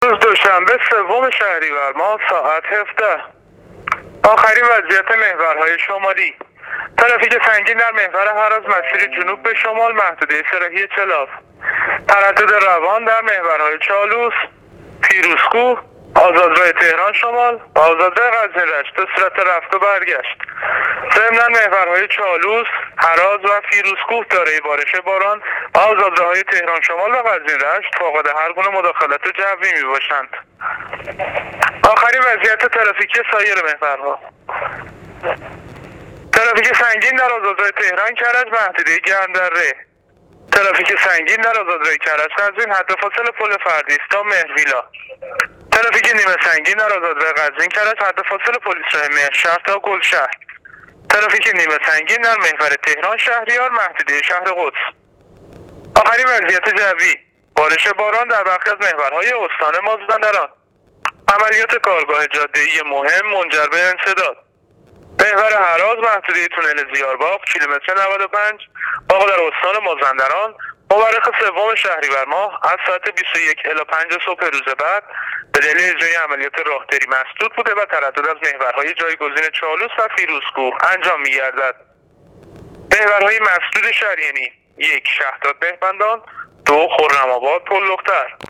گزارش رادیواینترنتی از وضعیت ترافیکی جاده‌ها تا ساعت ۱۷ سوم شهریورماه